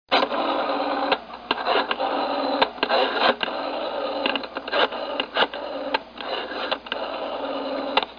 1 channel
DIAL.mp3